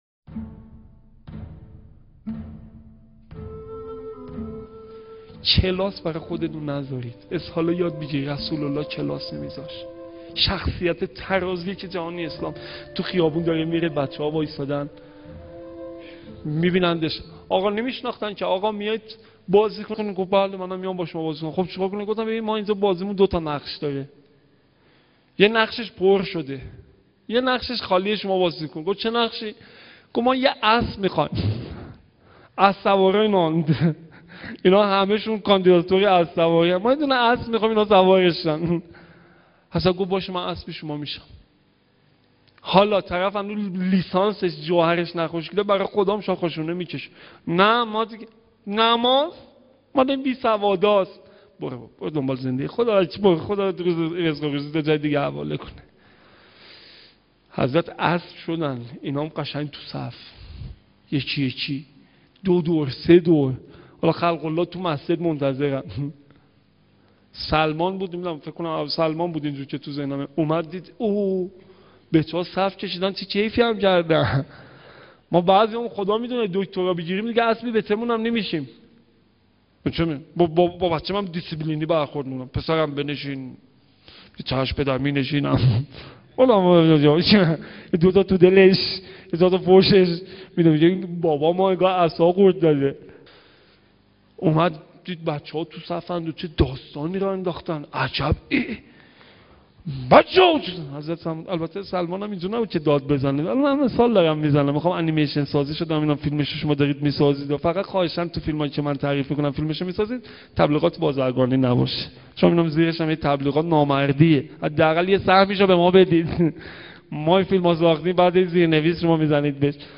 سخنرانی | الگوبرداری از برخورد پیامبر(ص) با اقشار مختلف
هیأت انصار المهدی(عج) شهرکرد